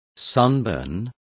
Complete with pronunciation of the translation of sunburn.